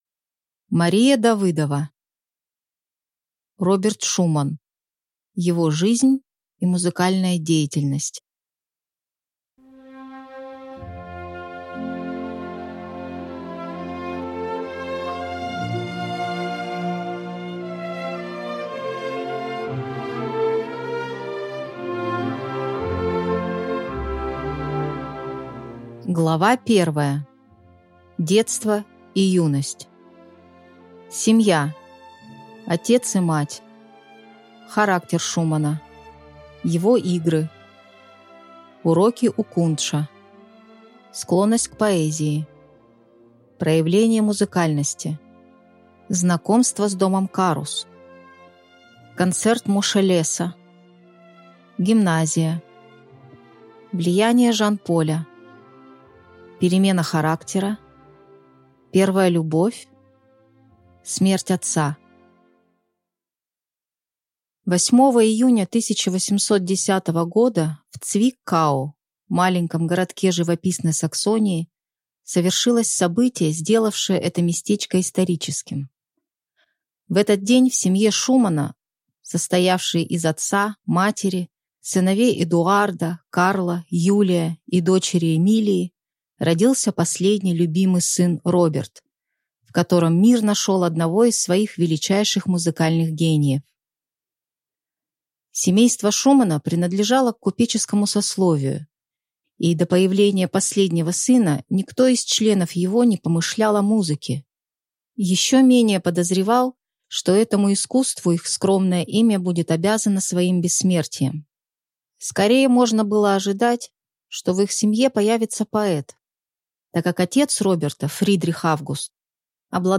Аудиокнига Роберт Шуман. Его жизнь и музыкальная деятельность | Библиотека аудиокниг